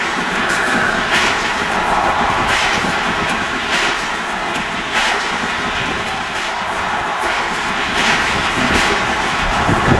Сильная метель:
snowstorm2.wav